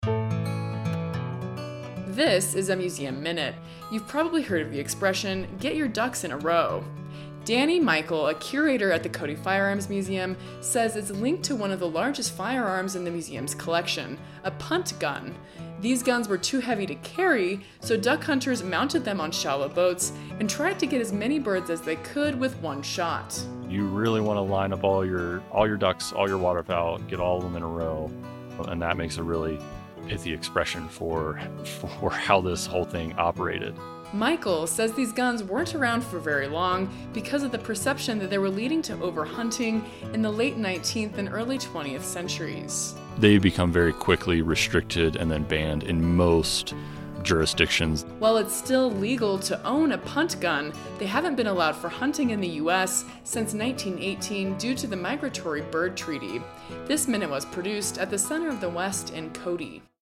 A 1-minute audio snapshot highlighting a museum object from the collection of the Buffalo Bill Center of the West.